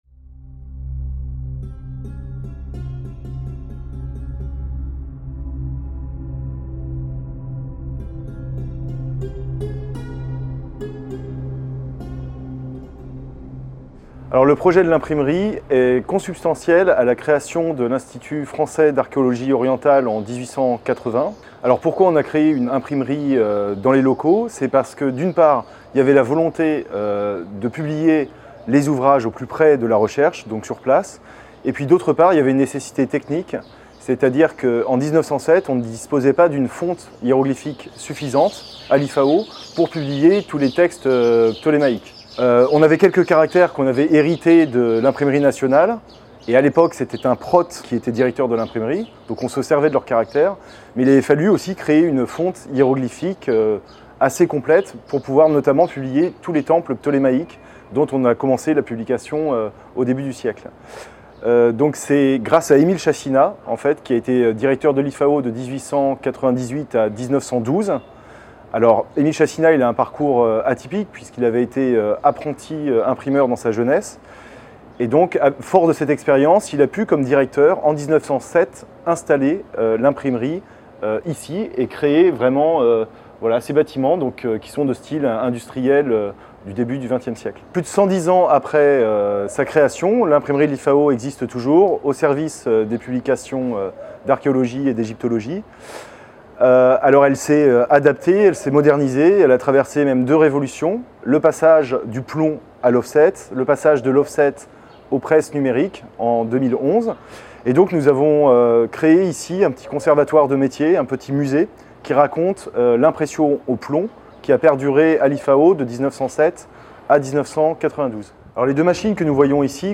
Visite guidée du musée de l'Imprimerie, à l'IFAO | Canal U